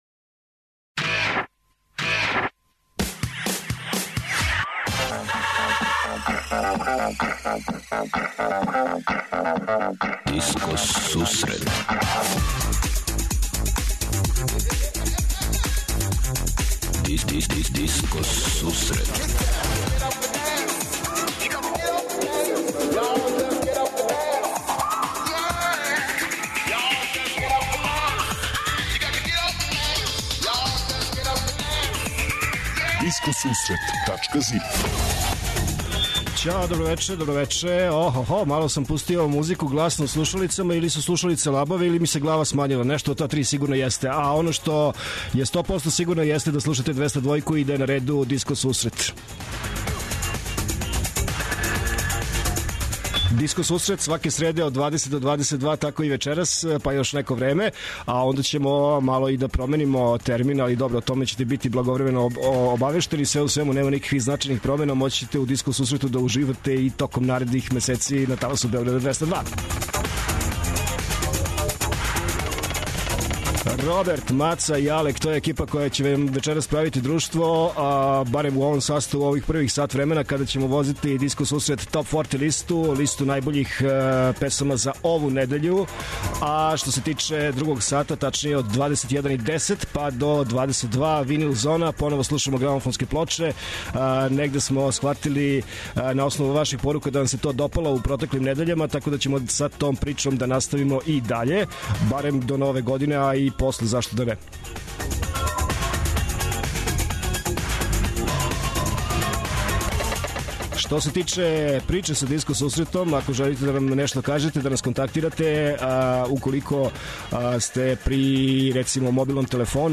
Oд 20ч Диско Сусрет Топ 40 - Топ листа 40 тренутно највећих хитова који пуне диско подијуме широм света. Од 21:10ч Винил Зона - Слушаоци, пријатељи и уредници Диско Сусрета за вас пуштају музику са грамофонских плоча.
преузми : 56.32 MB Discoteca+ Autor: Београд 202 Discoteca+ је емисија посвећена најновијој и оригиналној диско музици у широком смислу, укључујући све стилске утицаје других музичких праваца - фанк, соул, РнБ, итало-диско, денс, поп.